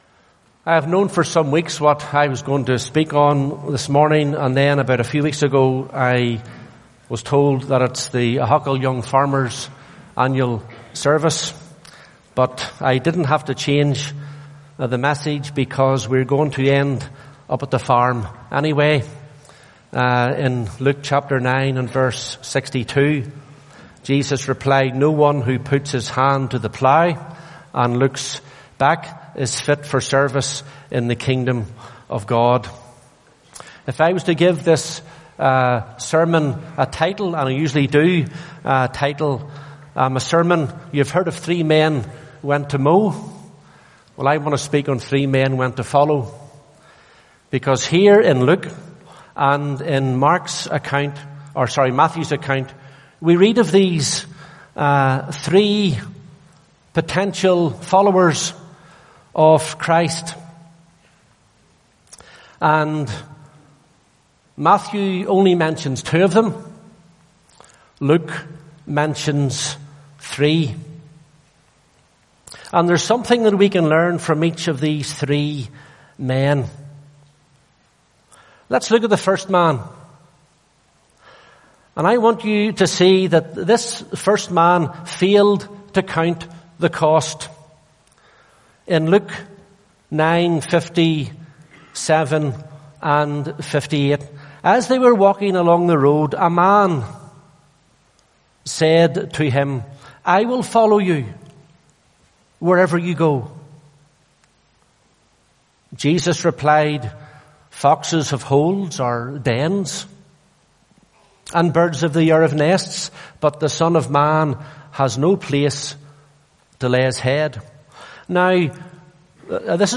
First Ahoghill – Young Farmers Service – 30.4.2017am